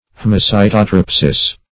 Search Result for " haemocytotrypsis" : The Collaborative International Dictionary of English v.0.48: Haemocytotrypsis \H[ae]m`o*cy`to*tryp"sis\ (-s[imac]`t[-o]*tr[i^]p"s[i^]s), n. [NL., fr. Gr. a"i^ma blood + ky`tos hollow vessel + tri`bein to rub, grind.]